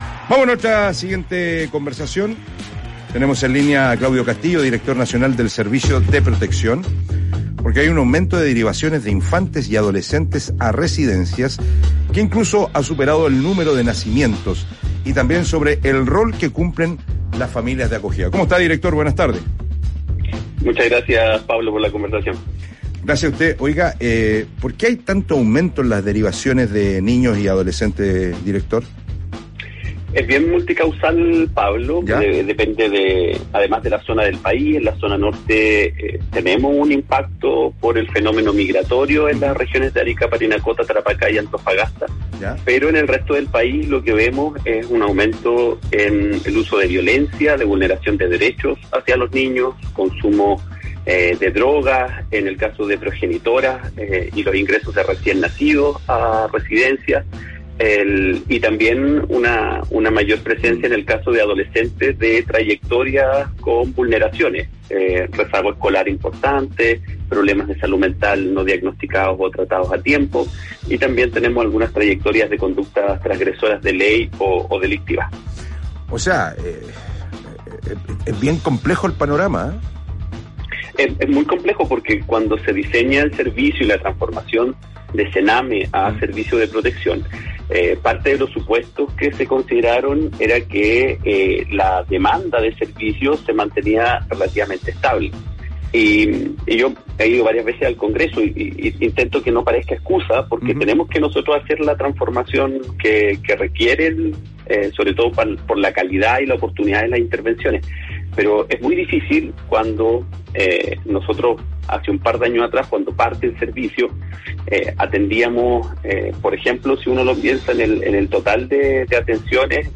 En entrevista con el programa Expresso PM de la radio Biobío, el director del Servicio de Protección, Claudio Castillo, señaló que, pese a la baja natalidad en nuestro país, ha habido un aumento de las derivaciones de infantes y adolescentes a residencias.